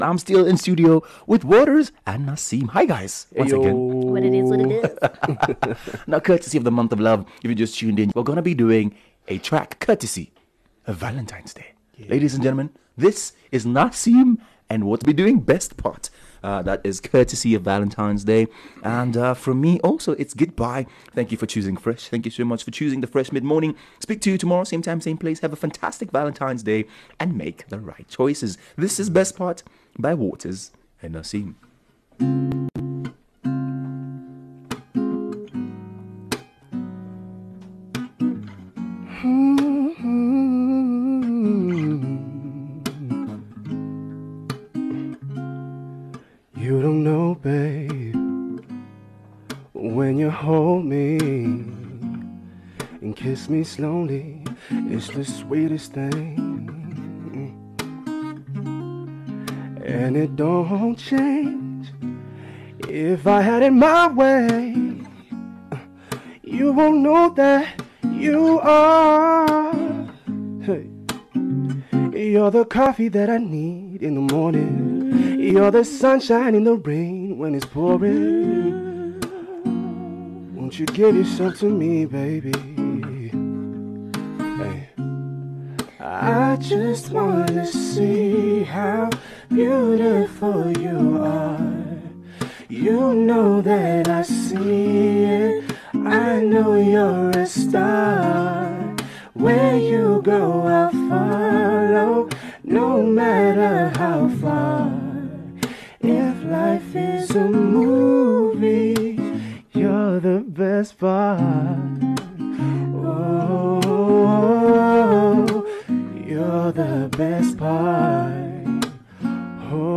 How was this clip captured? Live in studio